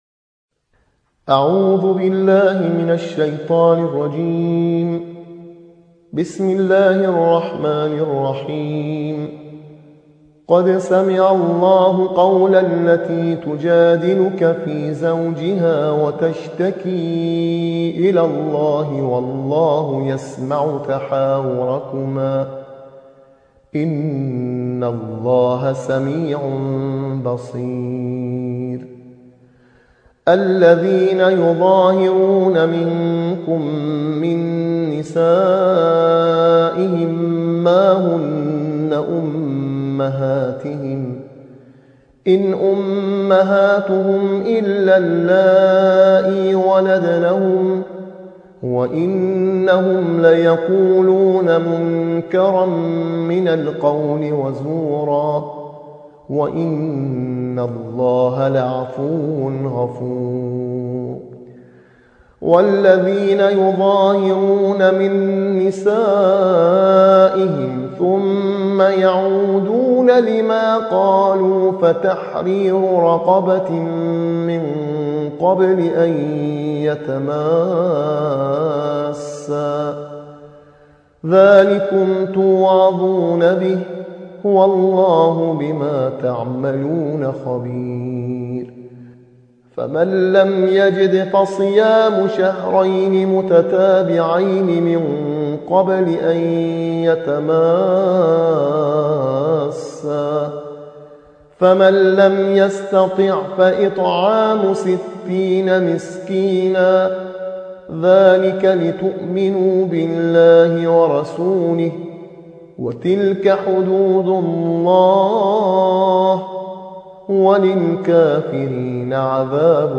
ترتیل‌خوانی جزء ۲۸ قرآن